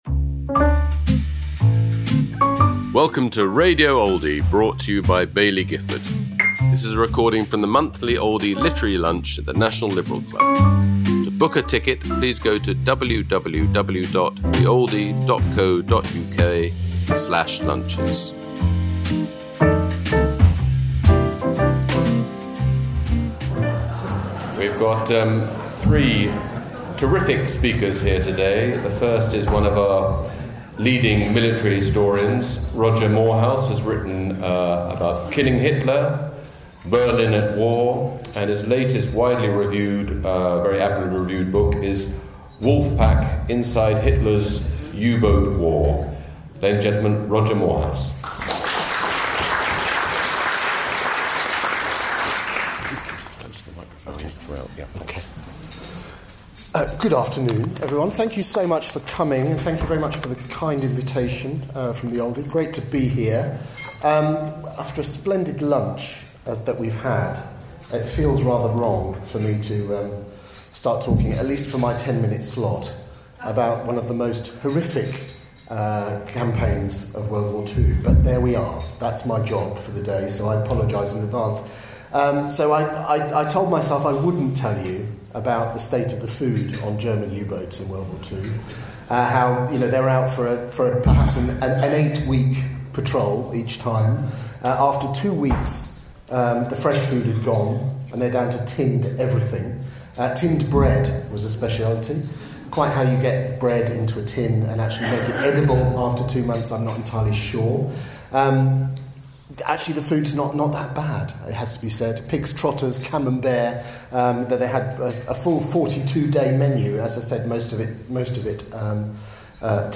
Roger Moorhouse speaking about his new book, Wolfpack: The Gripping New World War Two History Taking Us Inside Hitler's U-Boat War, at the Oldie Literary Lunch, held at London’s National Liberal Club, on November 25th 2025.